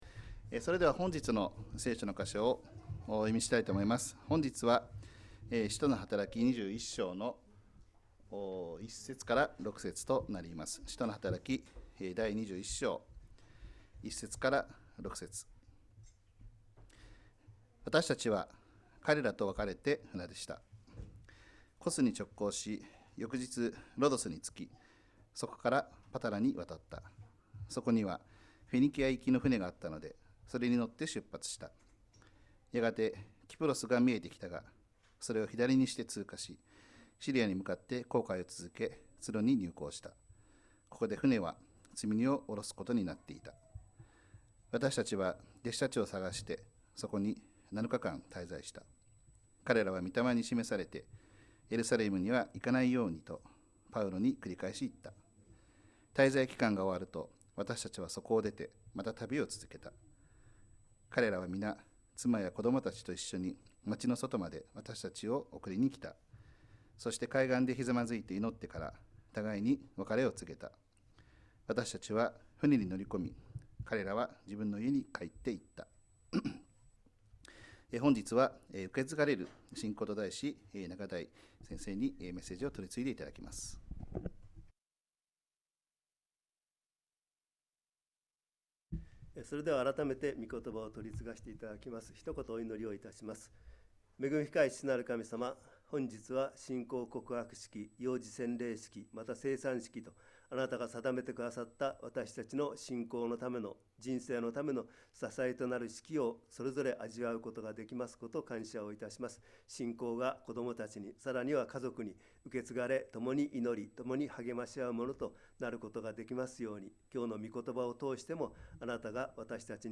2024年11月3日礼拝 説教「受け継がれる信仰」